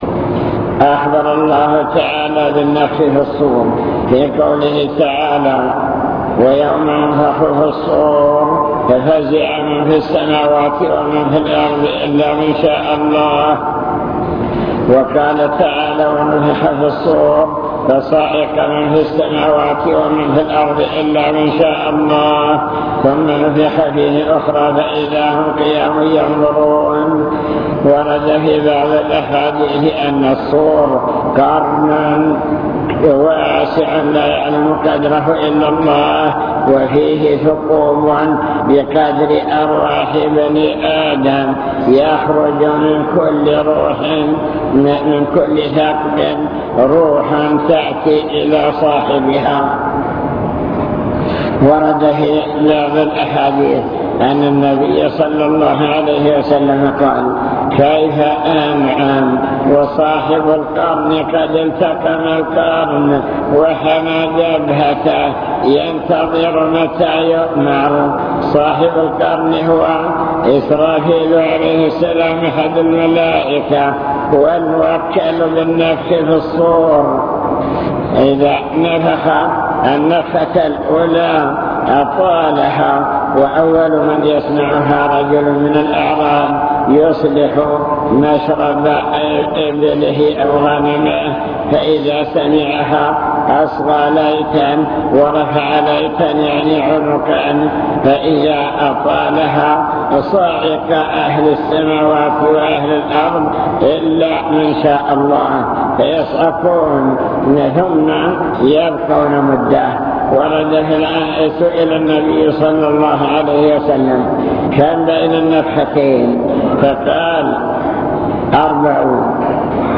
المكتبة الصوتية  تسجيلات - محاضرات ودروس  مواعظ وذكرى